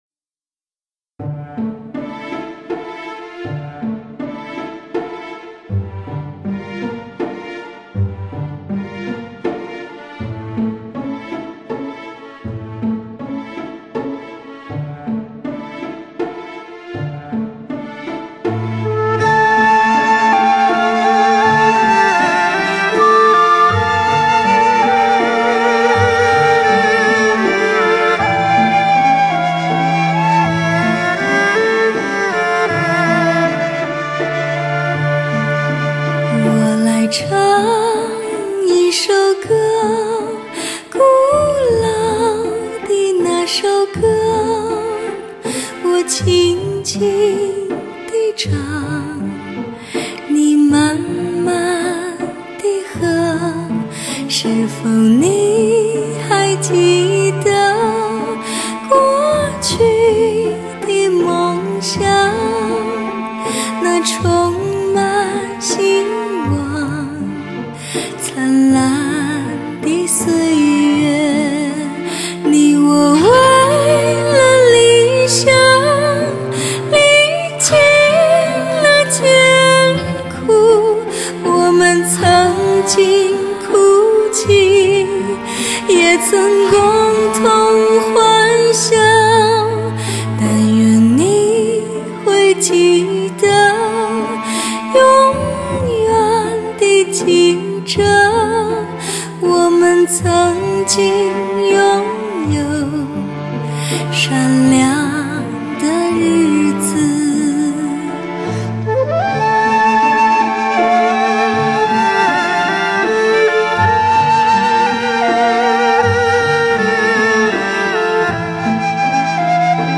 江南美景可以用听来“品味”晶莹剔透的人声质感，极佳乐器的精湛诠释，
诗情的画意，淡淡的雅韵，首首都让人爱不释手。